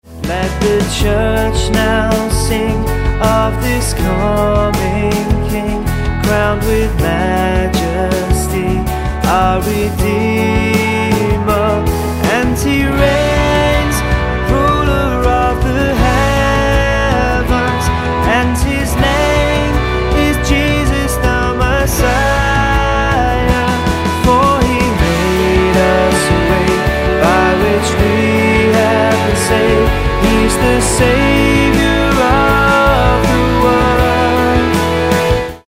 F#m